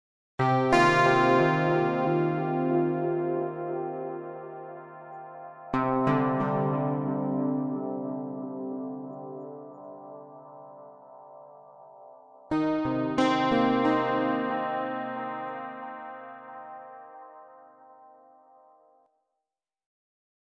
易拉宝吉他 " 易拉宝新913LGHI
描述：一个几乎类似于长笛的吉他样本，使用惊人的Ebow gizmo和我的Epiphone Les Paul吉他通过Marshall放大器制作。添加了一些混响。
标签： 环境 气氛 ebow 电子 吉他 音乐 加工
声道立体声